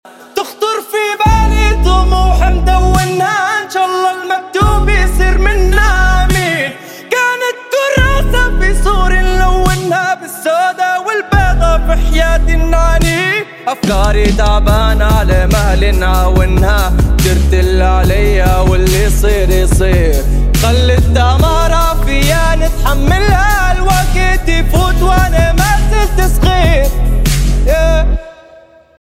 راب شارع ليبيا